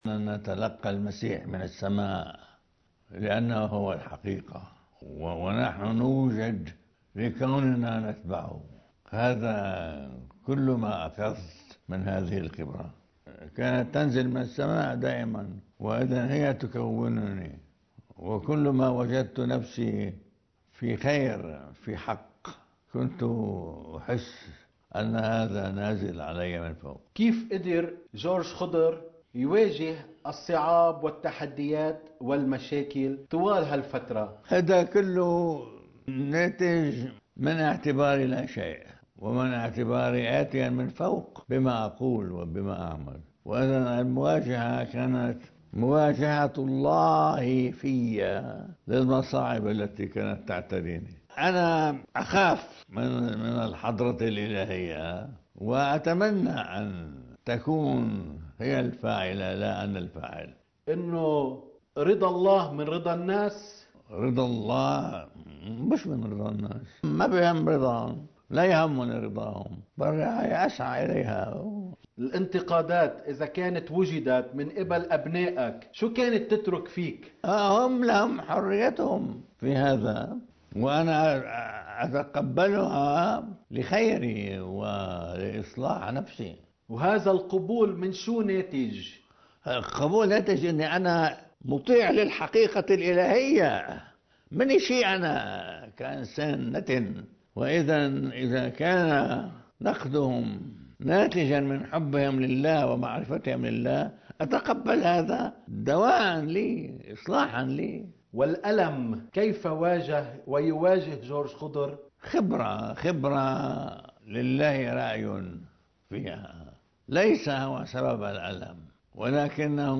مقتطف من حديث المطران جورج خضر لقناة “نورسات” ضمن زمن الفصح المجيد: (نيسان 2018)
المطران-خضر-حديث-شامل-عن-الموت-والفصح-والشباب-الأرثوذكسي.mp3